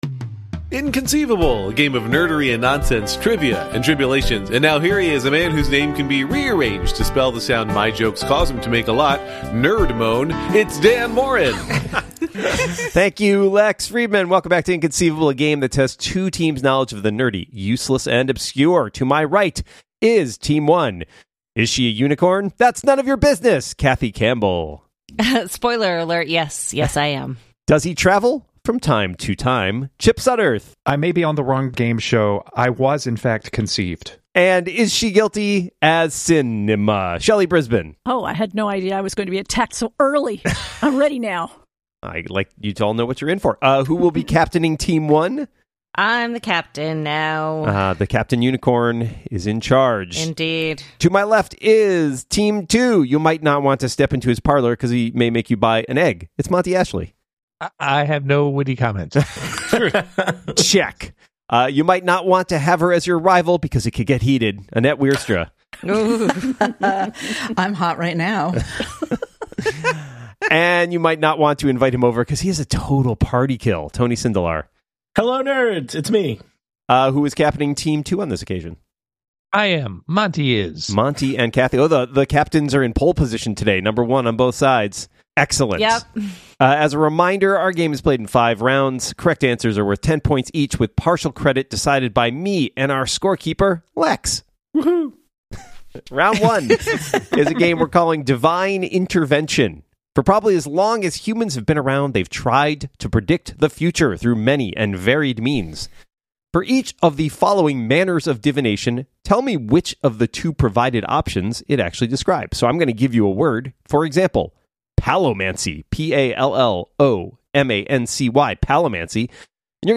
Welcome back to “Inconceivable!”, the show that tests two teams’ knowledge of the nerdy, the useless, and the obscure.